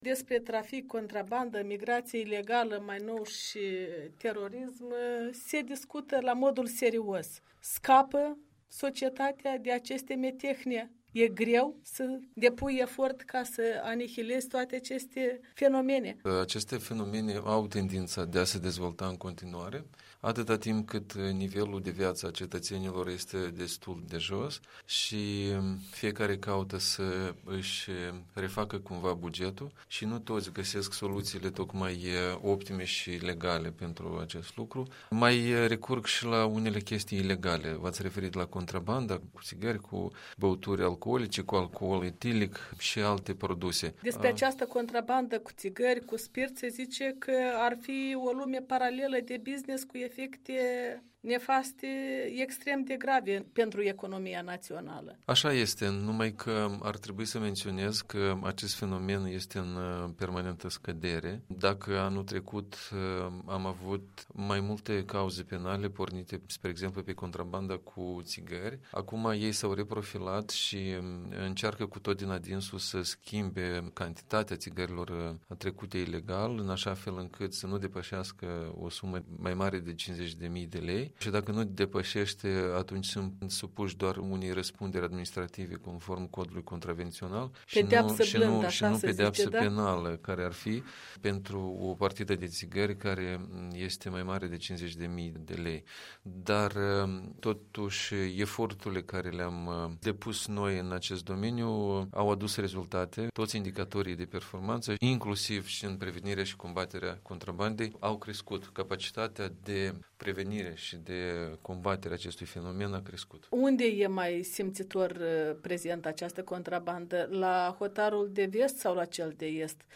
Interviu cu Dorin Purice